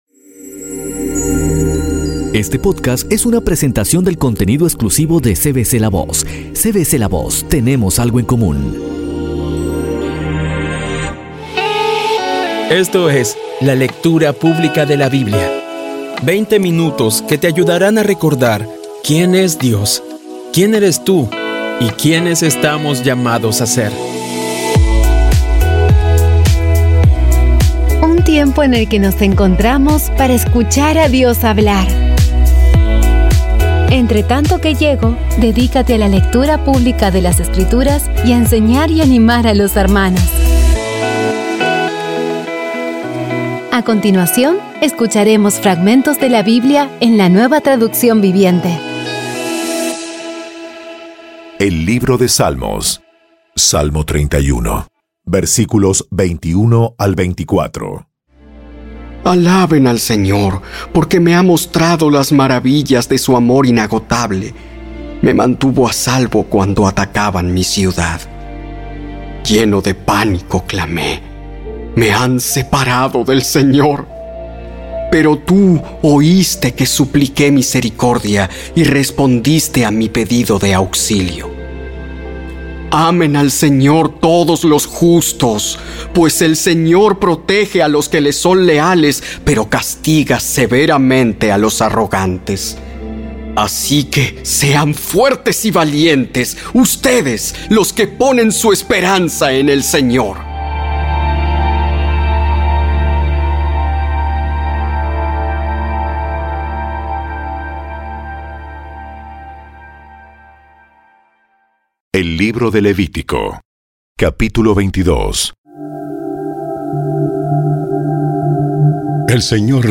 Audio Biblia Dramatizada Episodio 61
Poco a poco y con las maravillosas voces actuadas de los protagonistas vas degustando las palabras de esa guía que Dios nos dio.